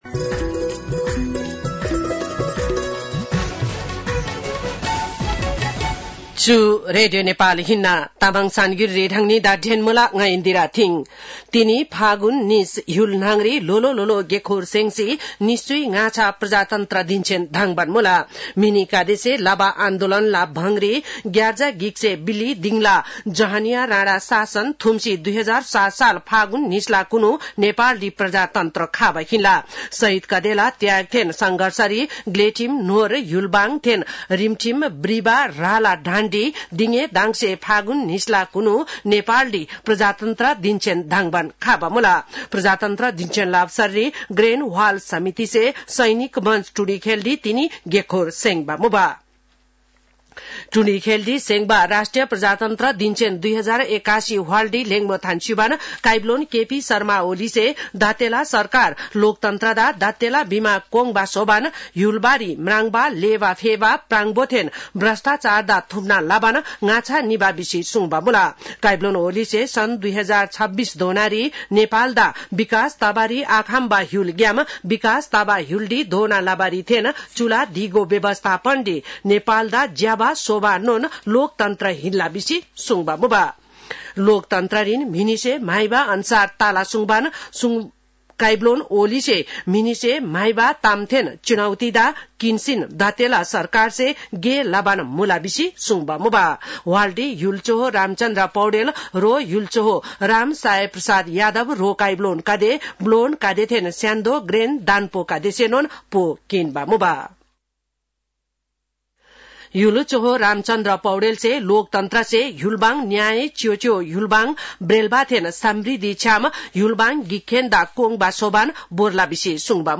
तामाङ भाषाको समाचार : ८ फागुन , २०८१